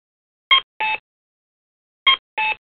• A cuckoo sound (accompanied by the walking person display) indicates that the pedestrian can cross in the north/south direction.
The APS sounds and locator tones automatically adjust to ambient sound levels.
Sample cuckoo sound:
9860-north-south_cuckoo.mp3